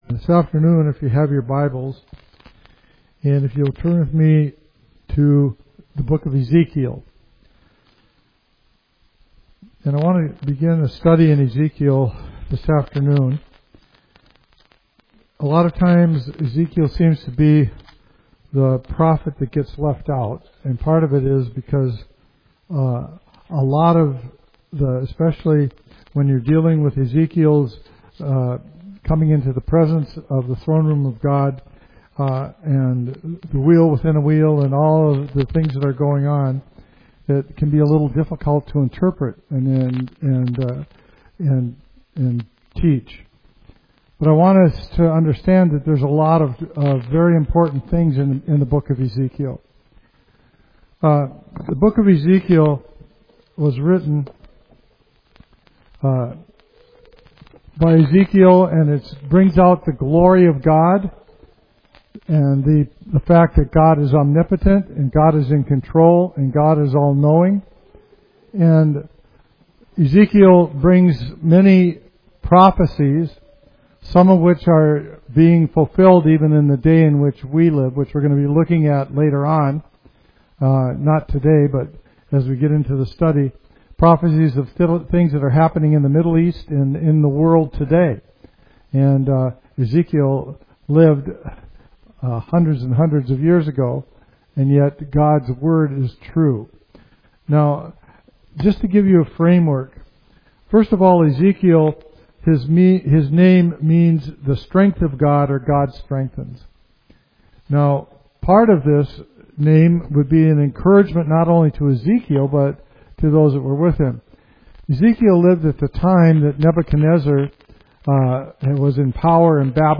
Study Ezekiel 1:1–18 and explore the prophet’s breathtaking vision of God’s glory, the four living creatures, and the wheels within wheels. This sermon explains the symbolism, meaning, and relevance for believers today.